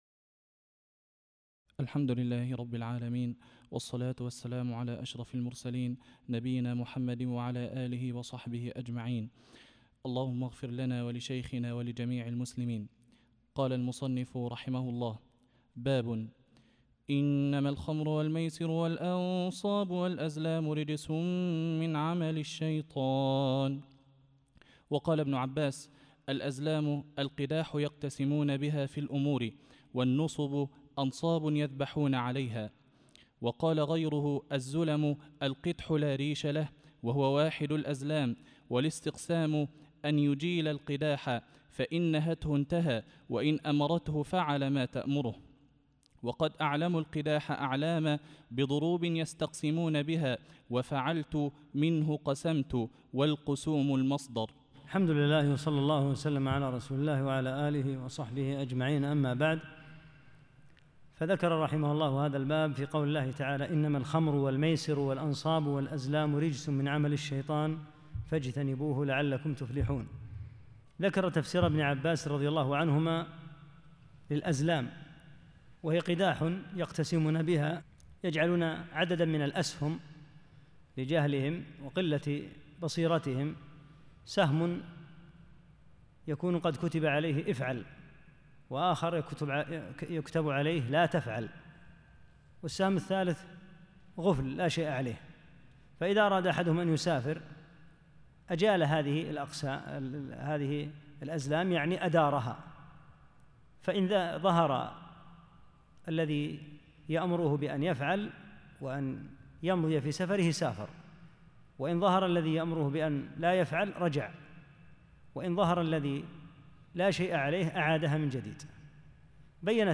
11 - الدرس الحادي عشر